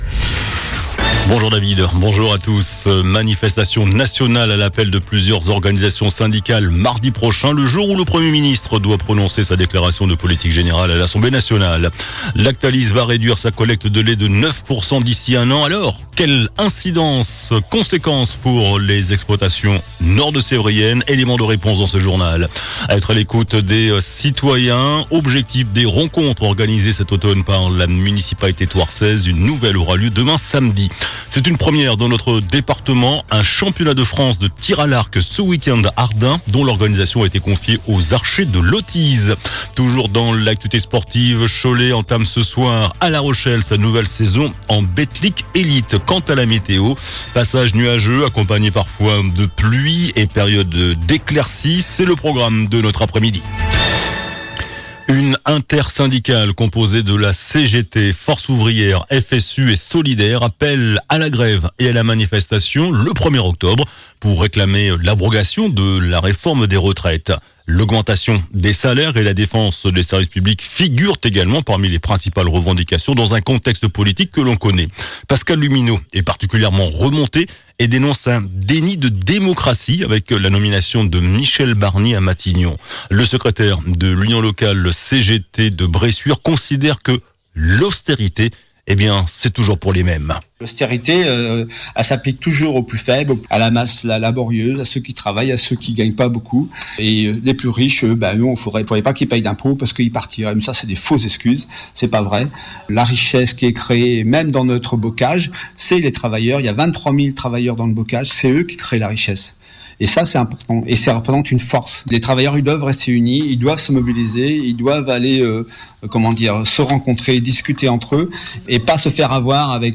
JOURNAL DU VENDREDI 27 SEPTEMBRE ( MIDI )